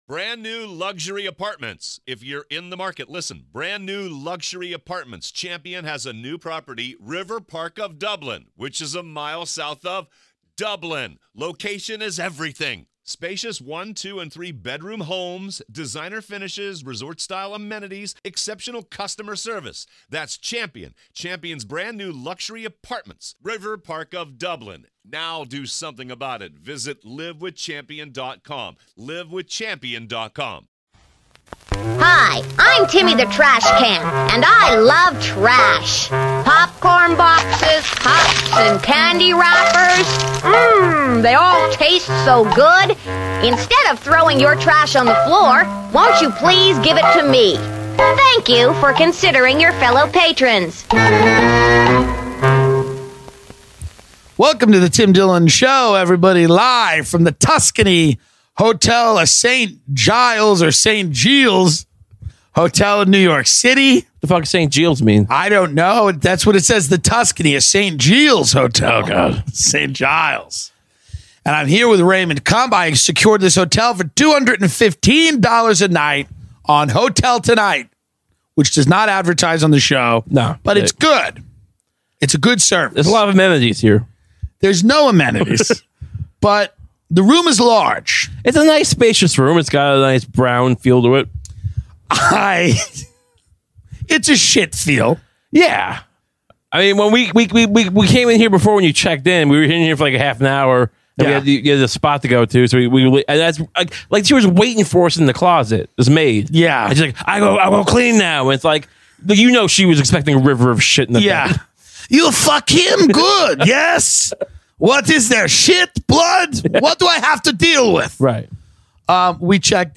Live from New York